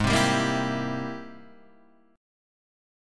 G#+M9 chord